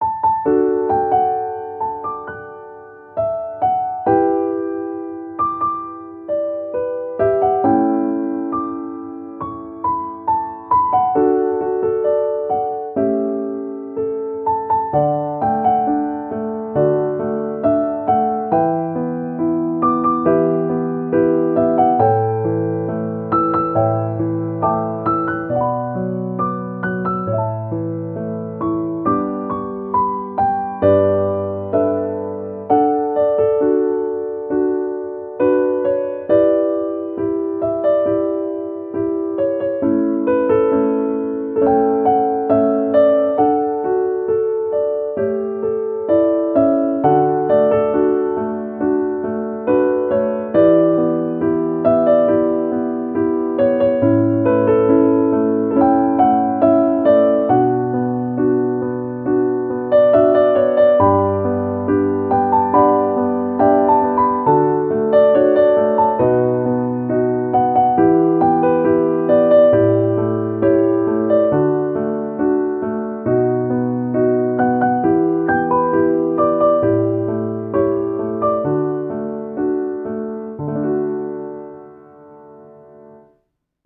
-oggをループ化-   しっとり 切ない 1:51 mp3